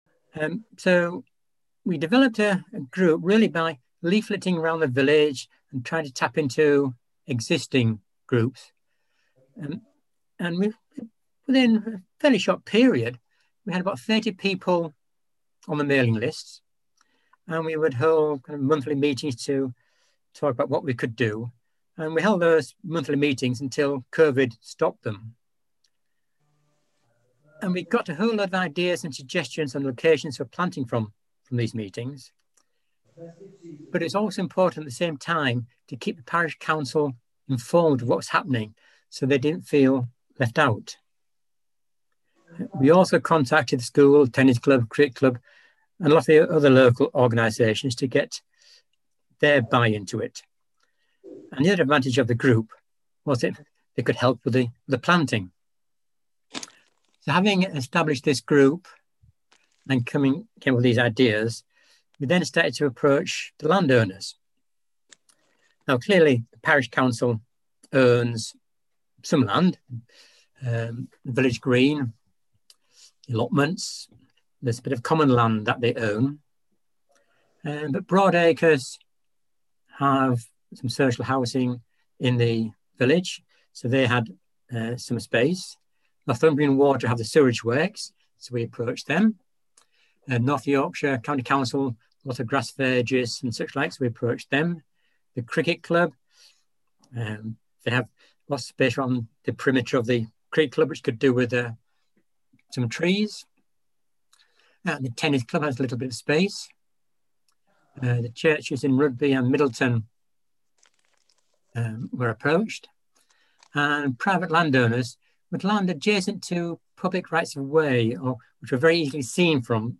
There was a variety of short presentations including tree planting in Stokesley, Hutton Rudby and Ingleby Greenhow and input from a local resident who is lucky enough to be planting his own wood and another who has developed a forest garden.
Here are presentations and recordings from the Zoom meeting.
tree-planting-in-hutton-rudby.m4a